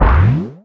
CARTOON_Boing_mono.wav